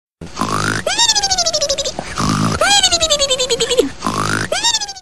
Sound Effects
Funny Snoring